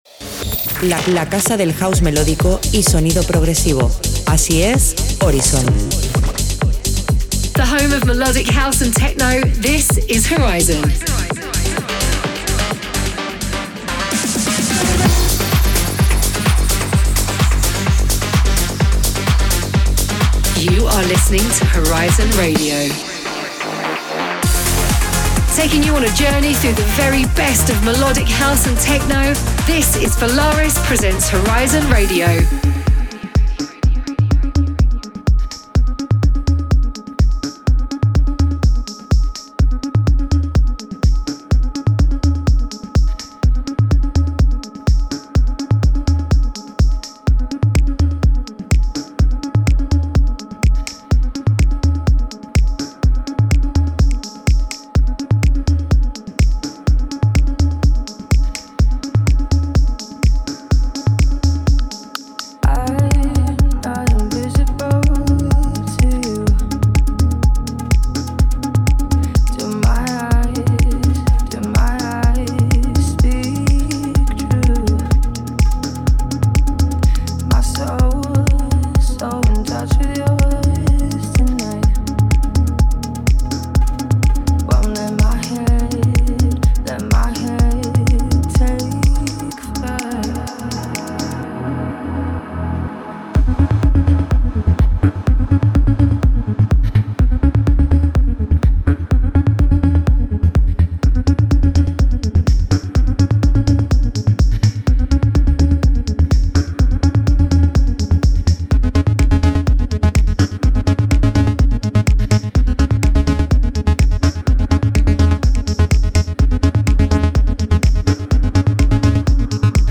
the home of melodic, house and techno.